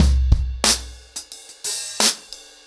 ROOTS-90BPM.23.wav